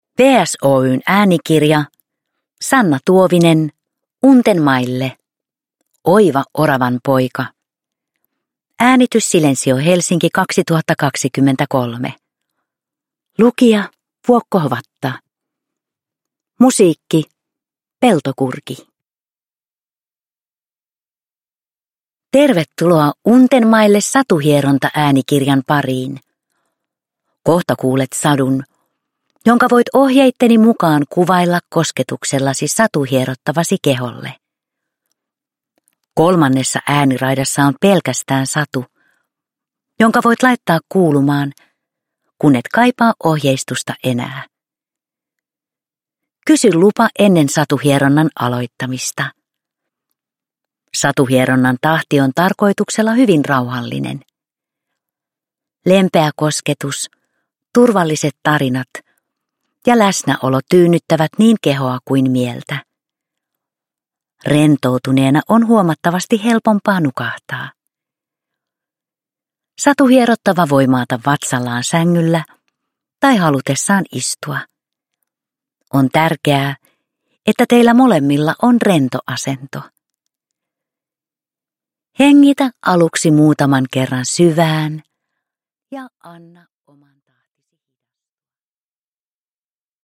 Satuhieronta-sadut vievät kokemaan untuvaista suloista pehmeyttä, metsän rauhoittavia ääniä sekä turvallista kotipesän tunnelmaa.
Untenmaille äänikirjat sisältävät teoksia varten sävelletyn rauhoittavan ja elämyksellisen musiikillisen äänimaiseman.